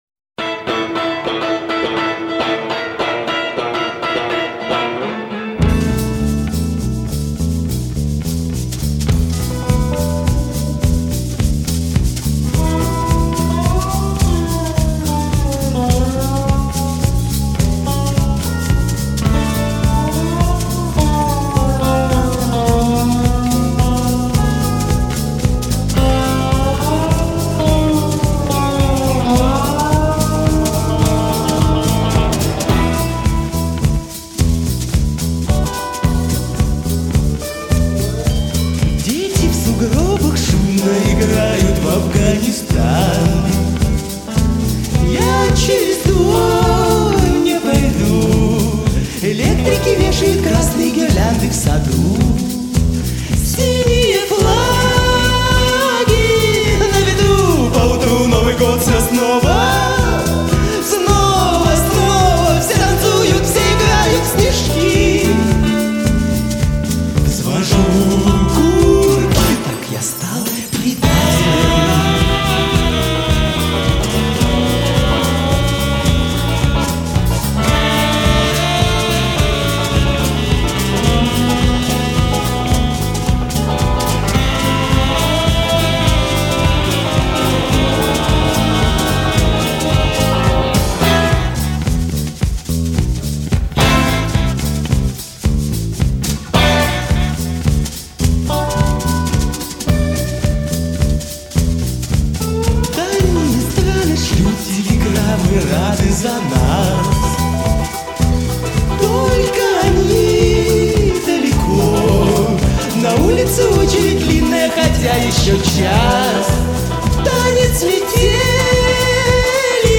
Рок Русский рок